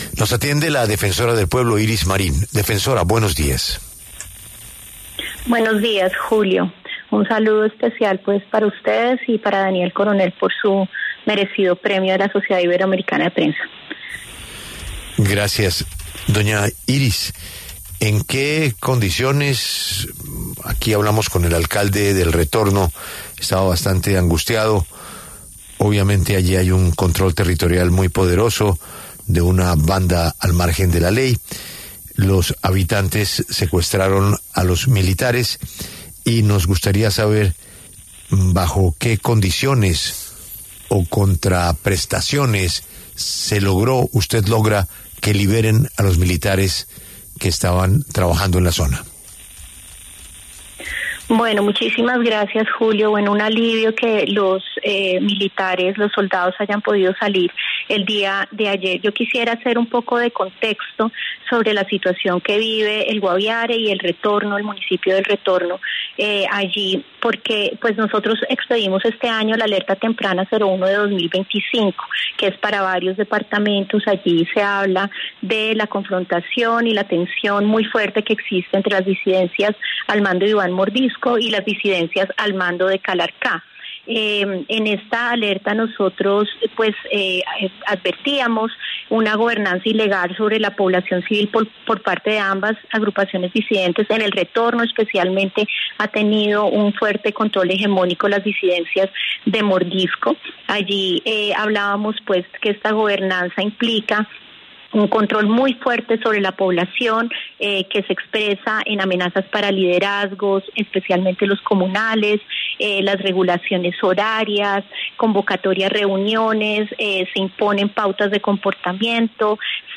Iris Marín, defensora del Pueblo, habló en La W y se refirió a los 33 militares que fueron secuestrados y recientemente liberados en el municipio de El Retorno, en el Guaviare.